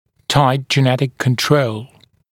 [taɪt ʤɪ’netɪk kən’trəul][тайт джи’нэтик кэн’троул]жесткий генетический контроль